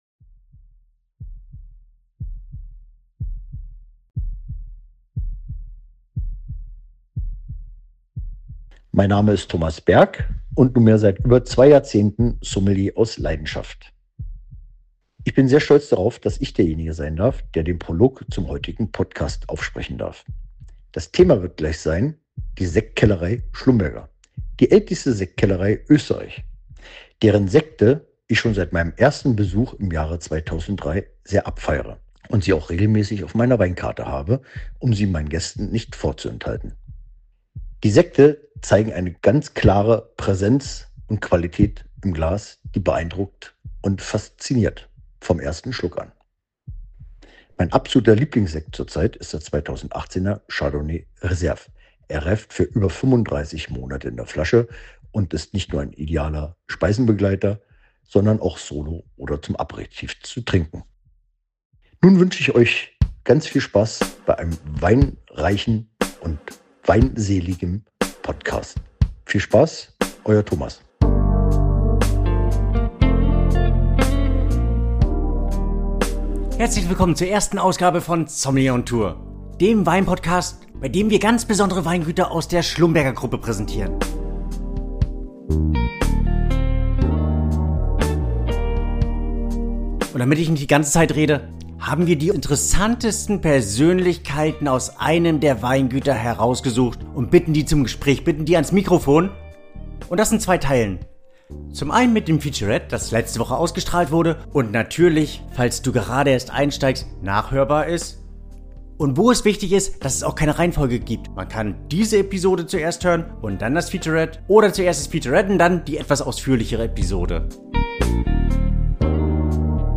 Er ist das tiefergehende Kapitel einer Erzählung, die von Anfang an größer war als ein einzelnes Interview.